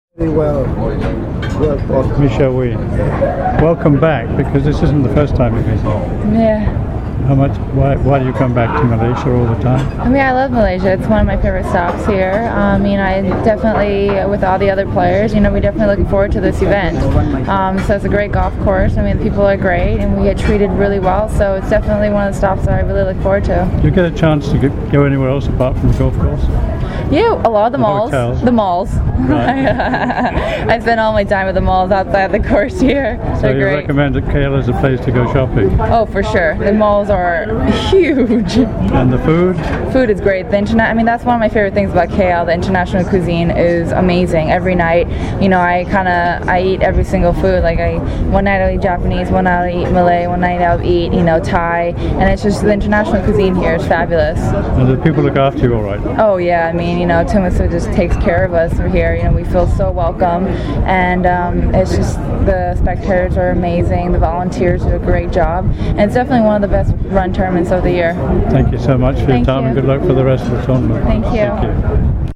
MGTA interviews Michelle Wie